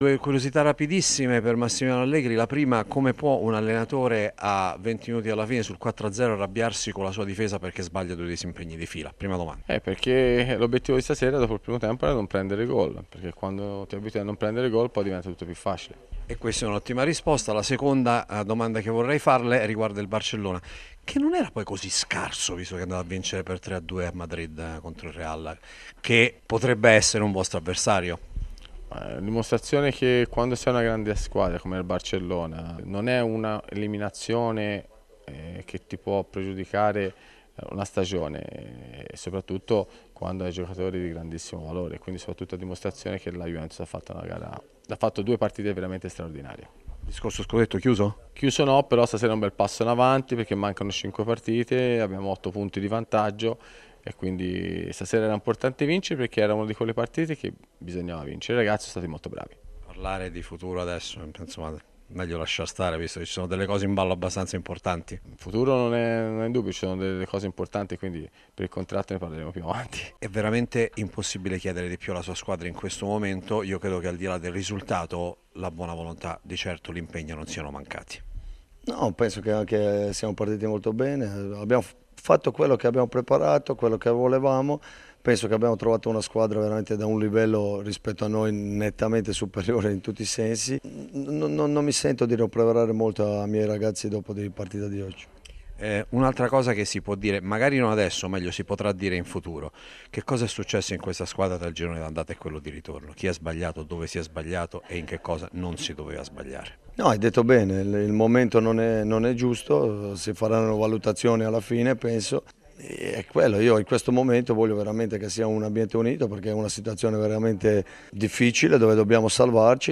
Interviste dagli spogliatoi di Juventus-Genoa - 'Domenica Sport' 23/04/2017